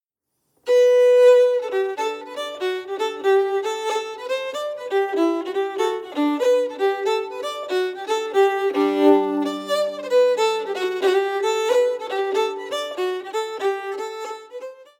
more than a whiff of the morris tradition
2. Melody recorded at full tempo